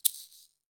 Index of /90_sSampleCDs/Roland L-CD701/PRC_Latin 2/PRC_Shakers
PRC SHAKER3.wav